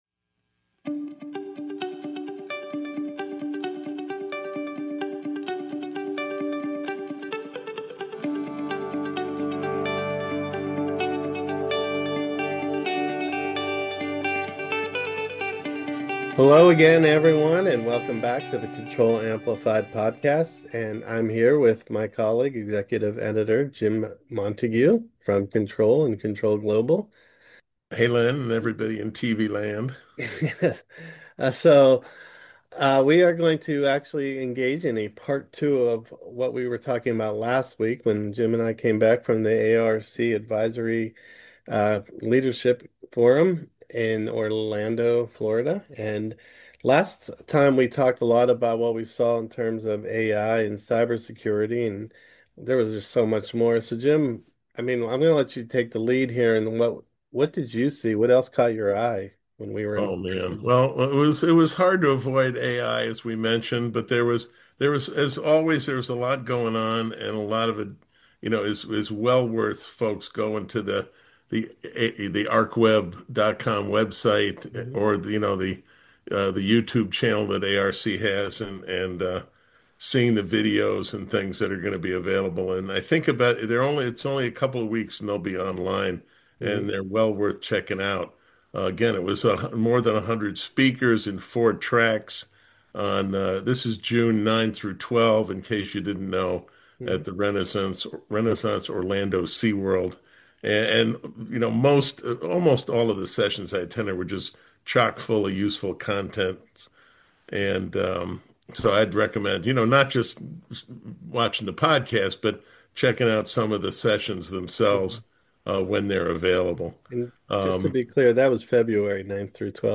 In part two of this two-part conversation